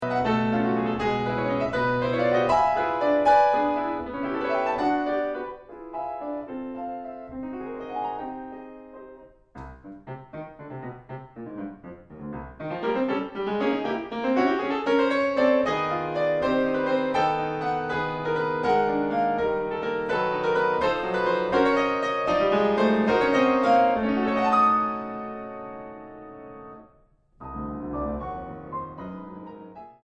Moderato 1.15